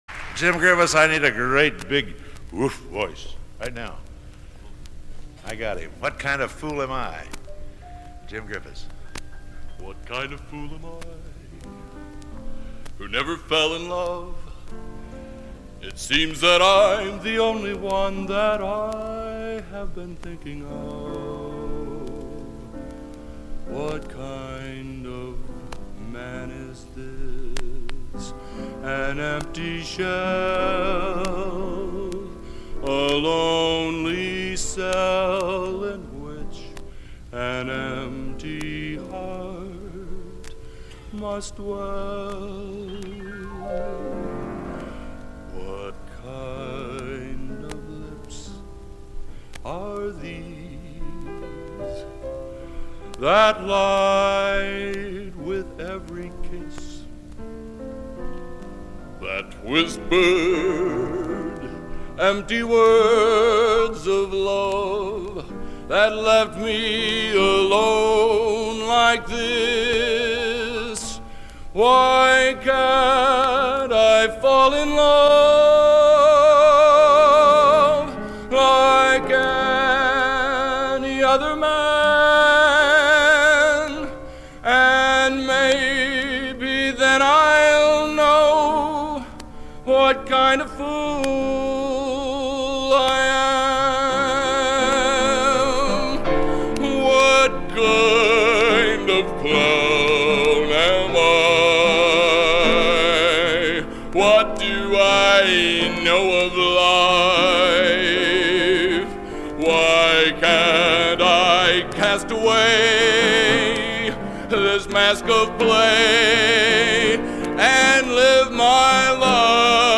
Location: West Lafayette, Indiana
Genre: | Type: Featuring Hall of Famer